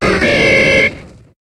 Cri de Galifeu dans Pokémon HOME.